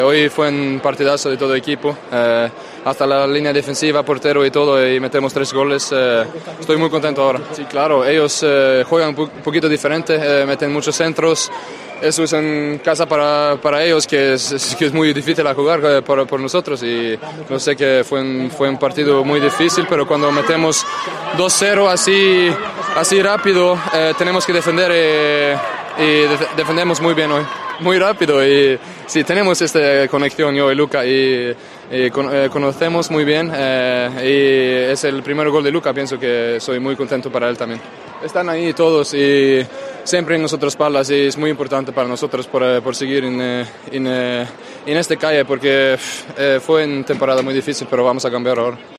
Declaraciones de Larsen tras la victoria celeste en Pamplona